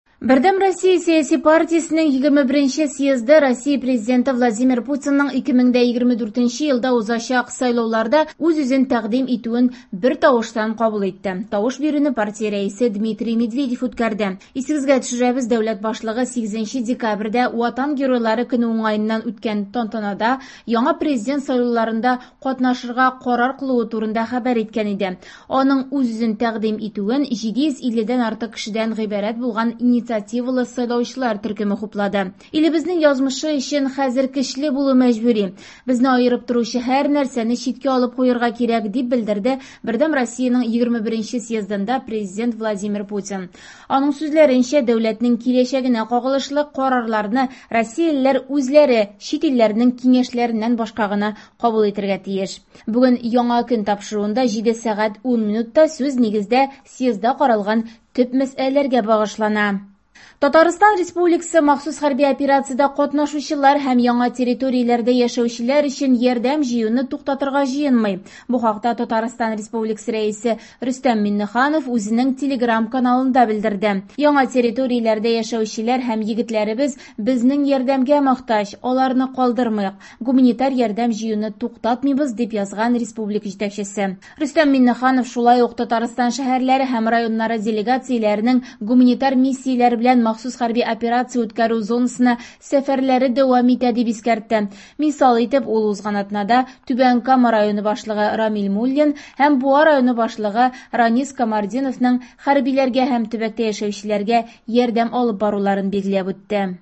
Яңалыклар (18.12.23)